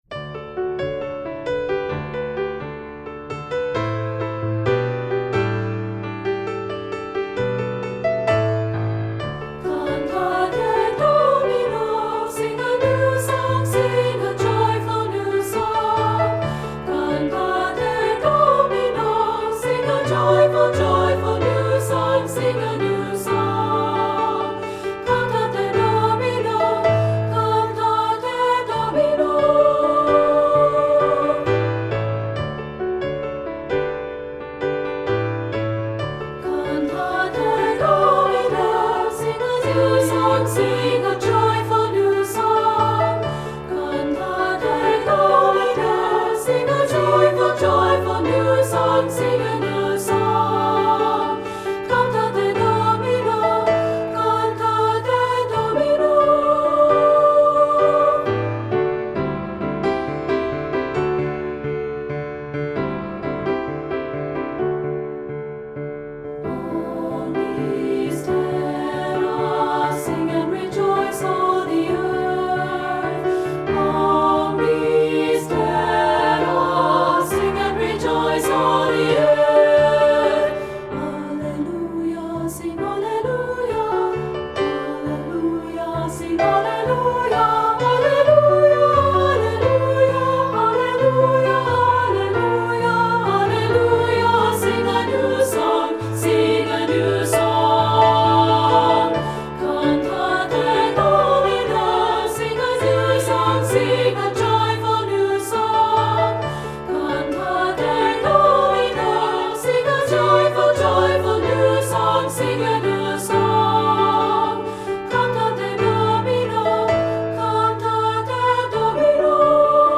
Voicing: SSA and Piano